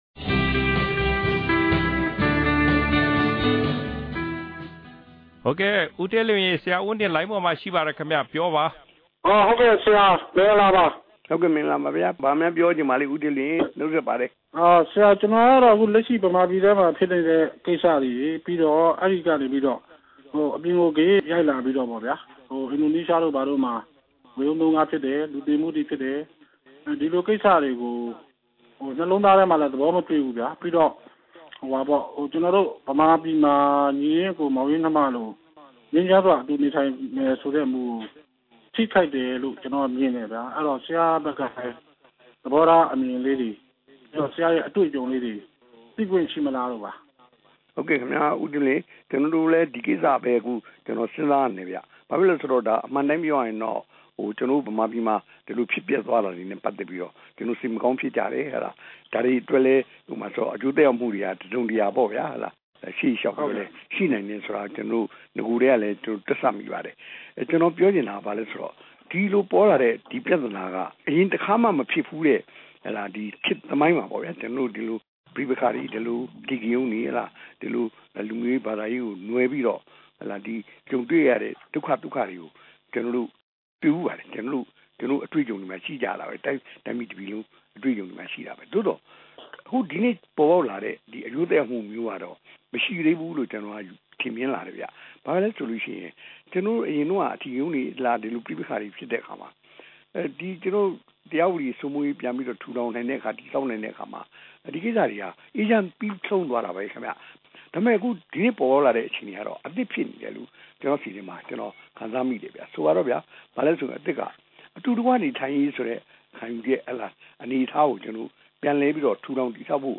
အမျိုးသားဒီမိုကရေစီအဖွဲ့ချုပ် ဗဟိုအလုပ်အမှုဆောင်အဖွဲ့ဝင် ဦးဝင်းတင်ကို ပြည်တွင်းပြည်ပက ပြည်သူတွေက အပတ်စဉ် RFA တယ်လီဖုန်းလိုင်းပေါ်ကတဆင့် တိုက်ရိုက်မေးမြန်း ပြောဆိုချက်တွေကို မှတ်တမ်းအဖြစ် ဖော်ပြထားပါတယ်။